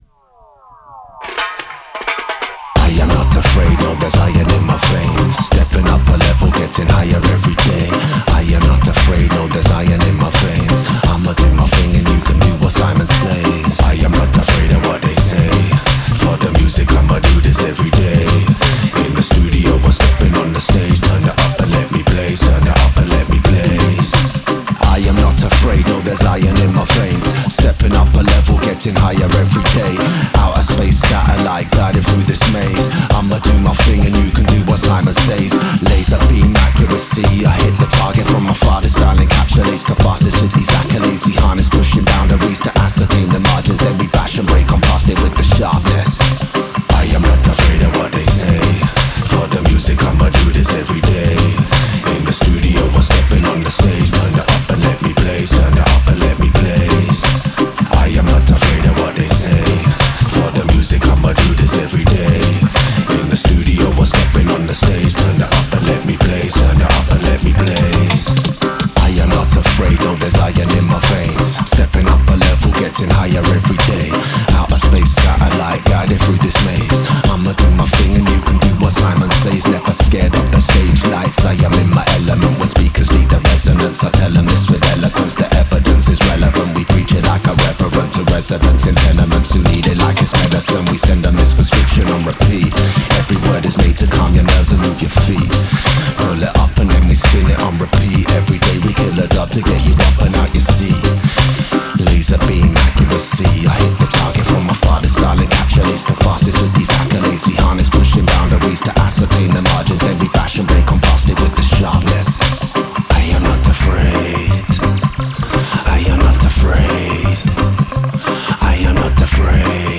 Genre:Reggae
クリーンなドライテイクから完全に処理されたFXバージョンまで、サウンドを自由に形作ることが可能です。
Fm / 85BPM
ドライ – クリーンで生々しいテイク
ダブプレート – クラシックなダブのタッチ
エコー – 空間的な深み
ローファイ – グリッティでノスタルジックな雰囲気
メガフォン – 力強くヴィンテージ感のあるサウンド
マルチ – レイヤードボーカル
リバーブ（EMTプレート） – 滑らかで豊かな残響
ボコーダー – 電子的なボーカル変換